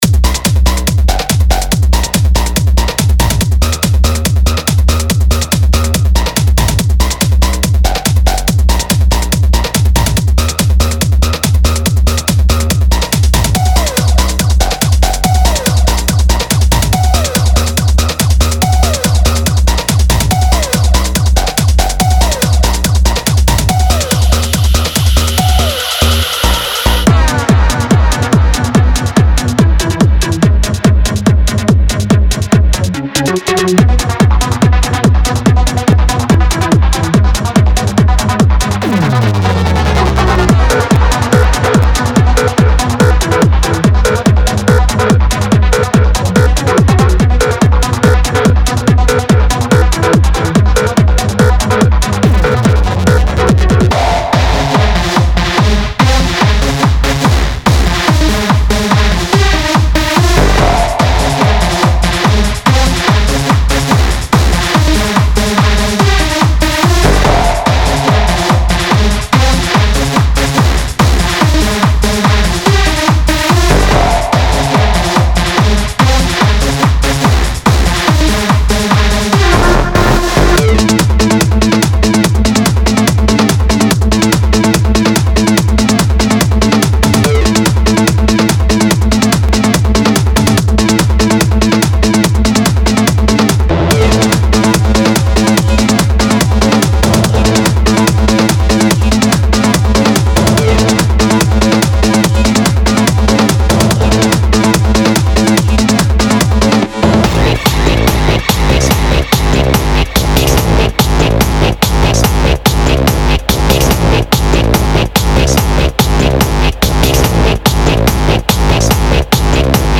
with edgier,harder, more aggressive loops
and synth phrases to keep the floor sweating
Intense SFX hits and production effects complete